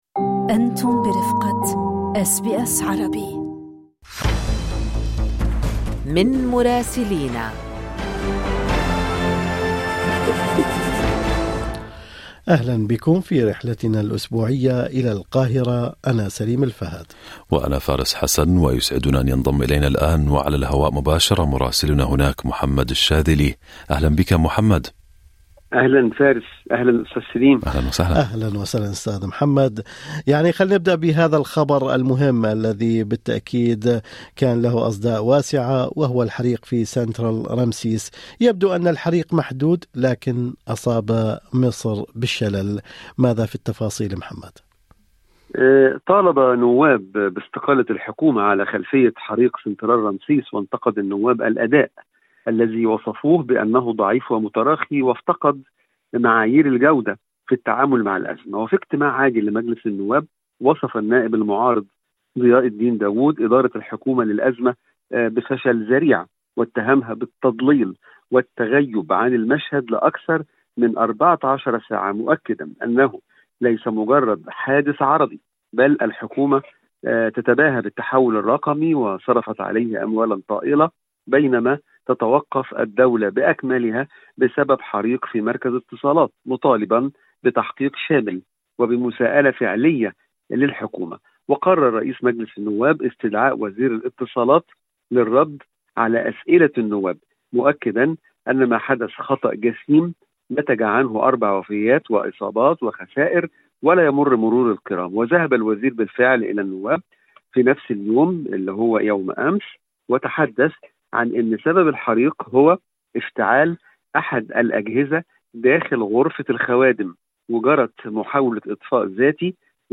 تداعيات حريق سنترال رمسيس وأخبار أخرى في تقرير مراسلنا في القاهرة